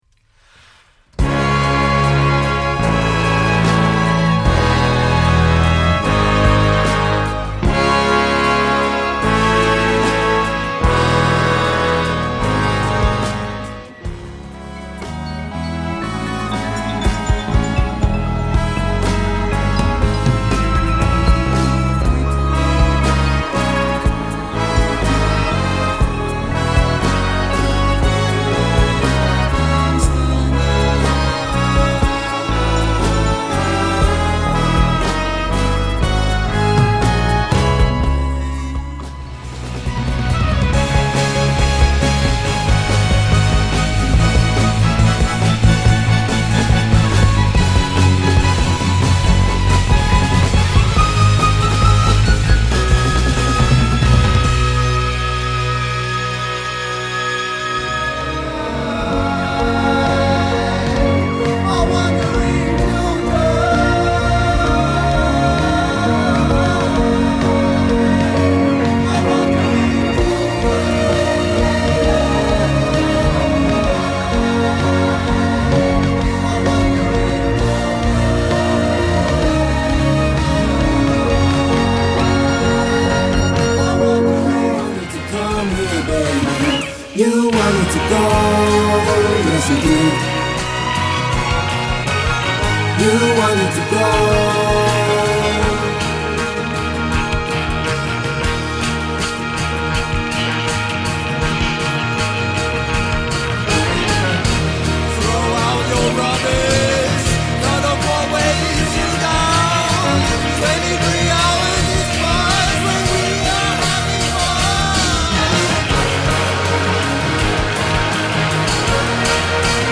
03+orchestral+writing+and+recording.mp3